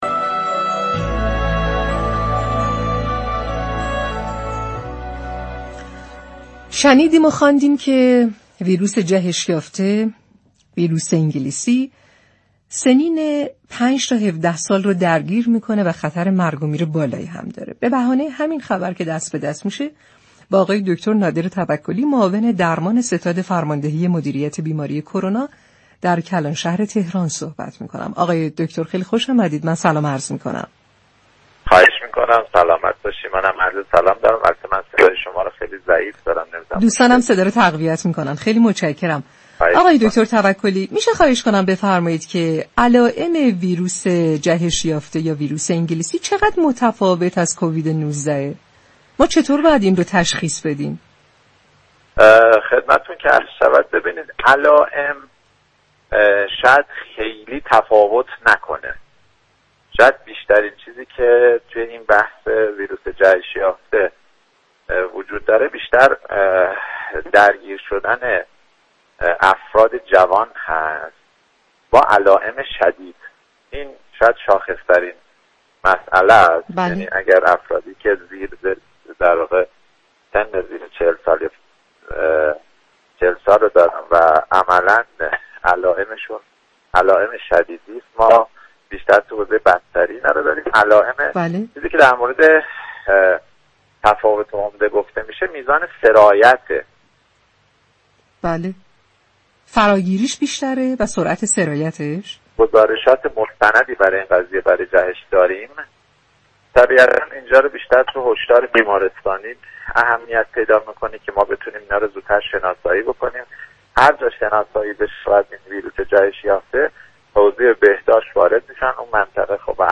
به گزارش پایگاه اطلاع رسانی رادیو تهران، نادر توكلی در گفتگو با برنامه « تهران ما سلامت» درباره علائم افتراقی كووید 19 با كرونای جهش یافته انگلیسی گفت: علائم این بیماری ها خیلی با هم تفاوت ندارد.